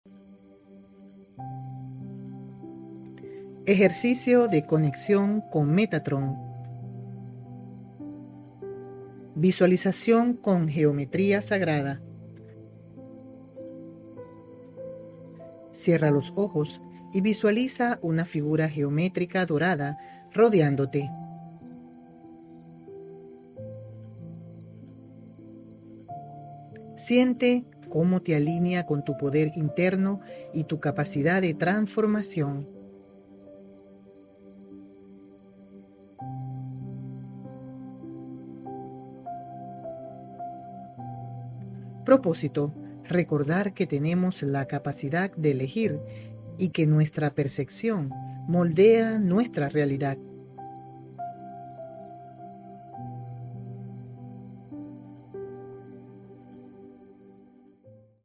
🔹 Visualización con Geometría Sagrada:
Curso-de-Milagros-Meditacion-Dia-8-2da-med.mp3